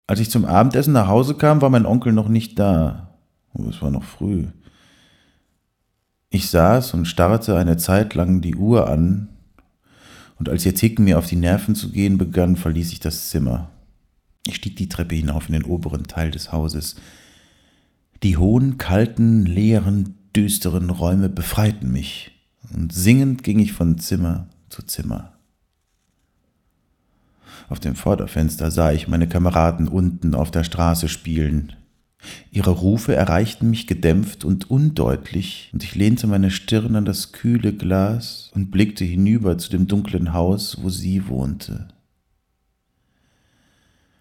Sprechprobe: Werbung (Muttersprache):
german voice over artist